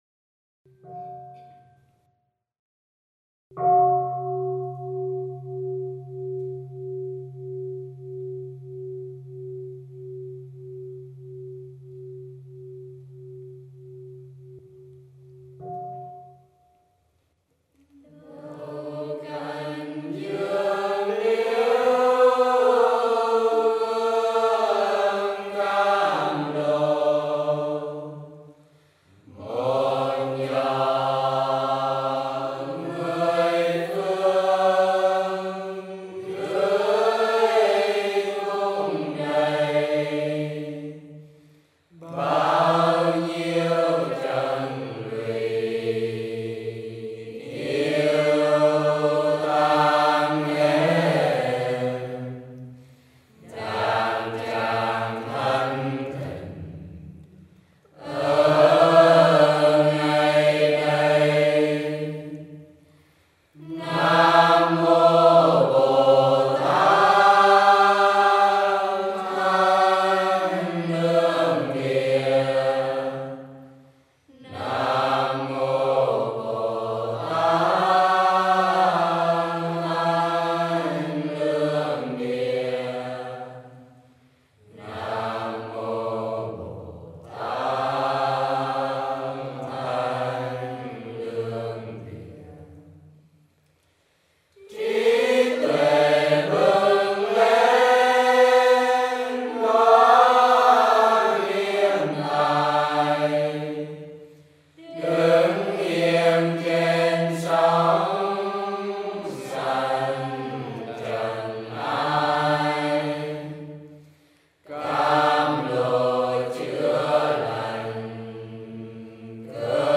Chants & Songs